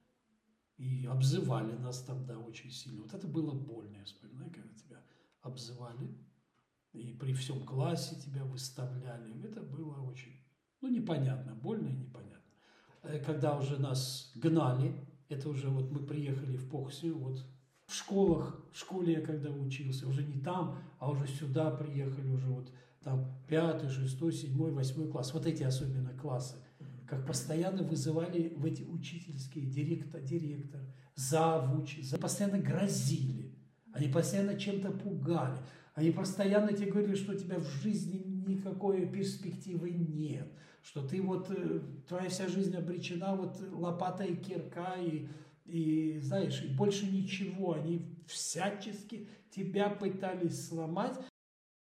Interviewsequenz  Erniedrigungen